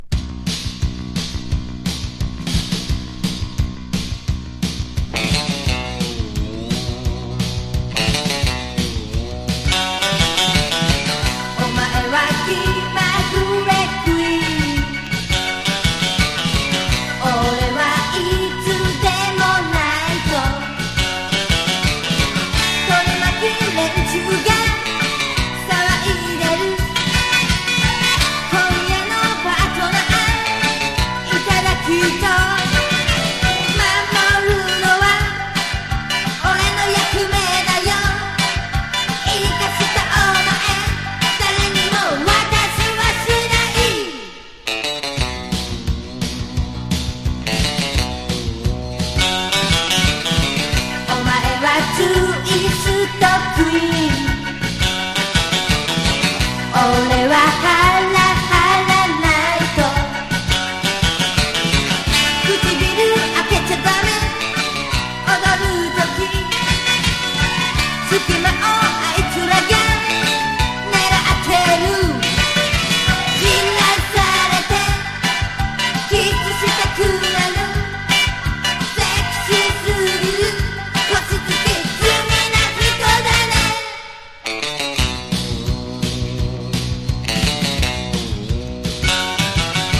ニューウェイヴ感あるツイスト・ロカビリーをバックに陽気に歌うロック歌謡
和モノ / ポピュラー# 70-80’S アイドル